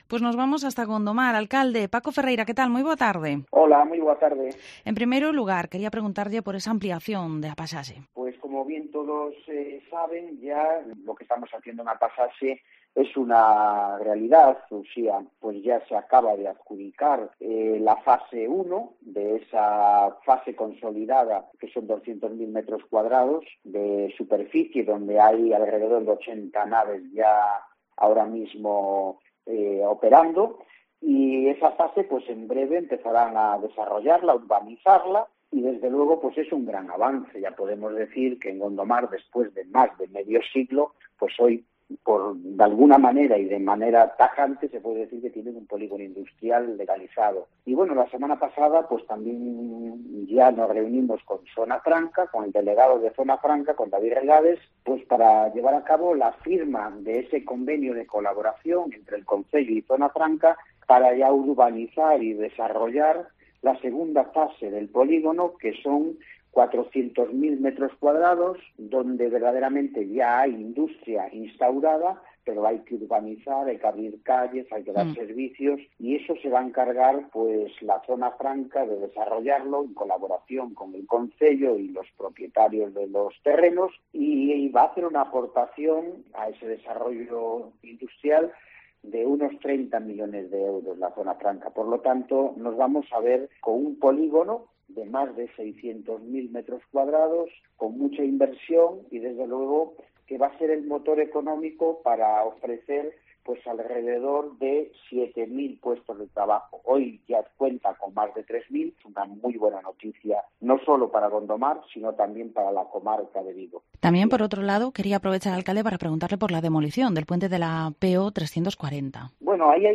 Entrevista Alcalde de Gondomar, Paco Ferreira